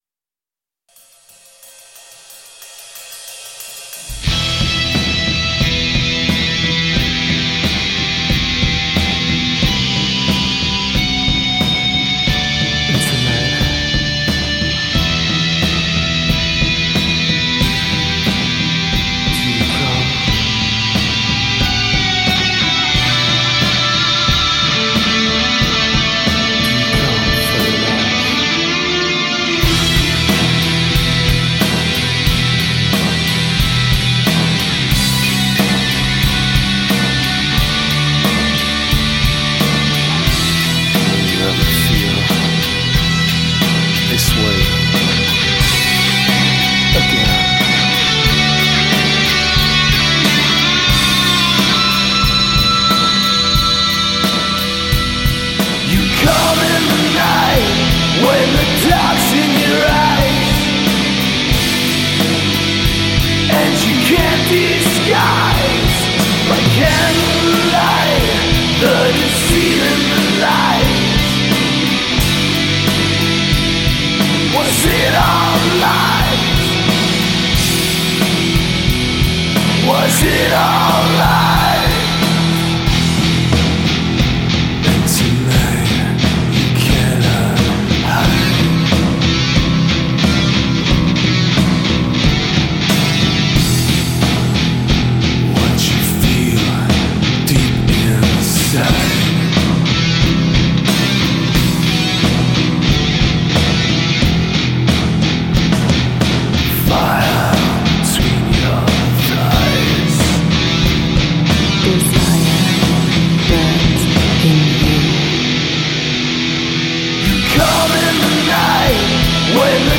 Style: Gothic Metal/Dark Metal Bandpage